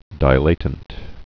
(dī-lātnt, dĭ-)